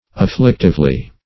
afflictively - definition of afflictively - synonyms, pronunciation, spelling from Free Dictionary Search Result for " afflictively" : The Collaborative International Dictionary of English v.0.48: Afflictively \Af*flic"tive*ly\, adv.